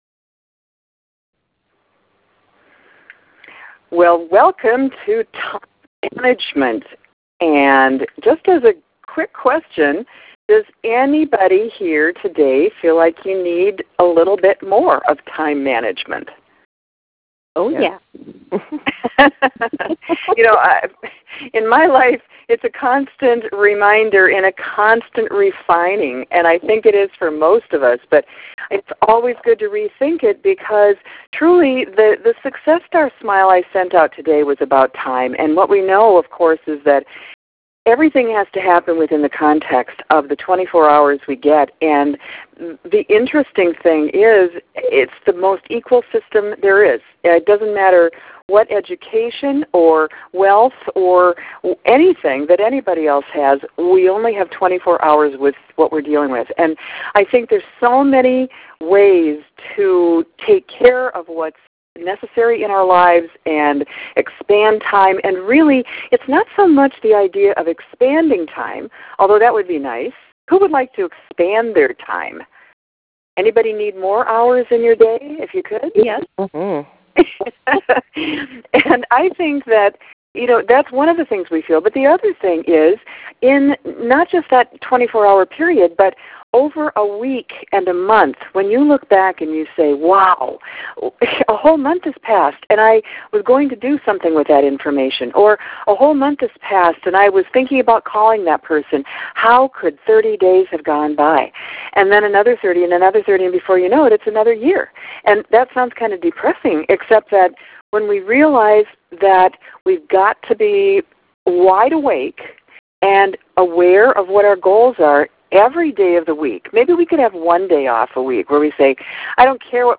Time Management Tele-Seminar Audio & Handout